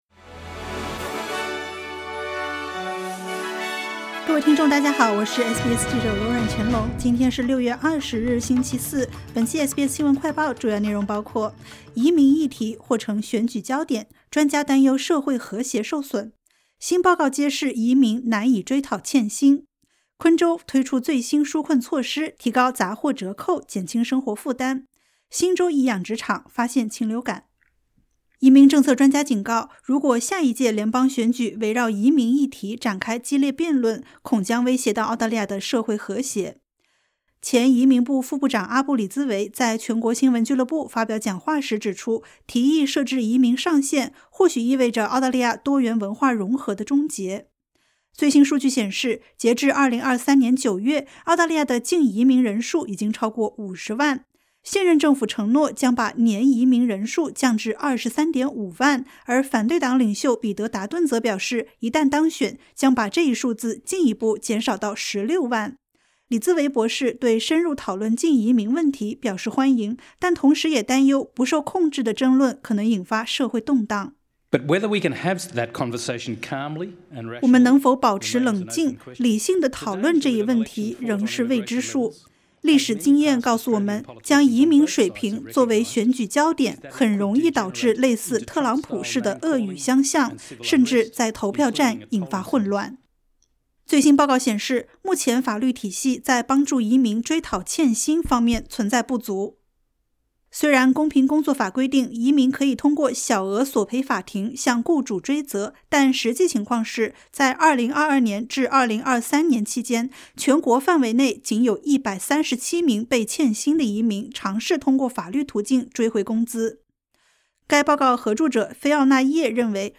【SBS新闻快报】移民议题或成大选焦点 专家忧社会和谐受损